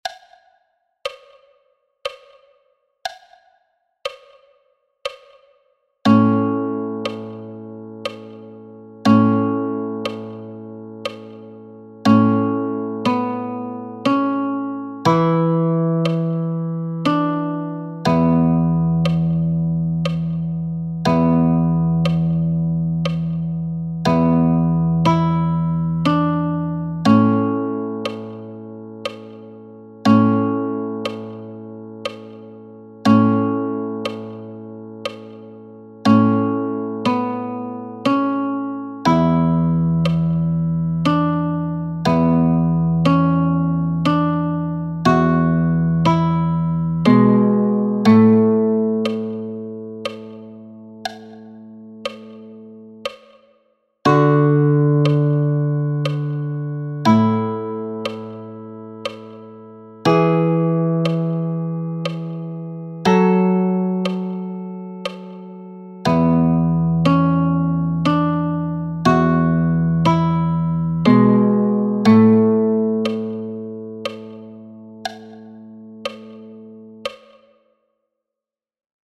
Liederbuch mit 35 einfachen Arrangements für die Gitarre.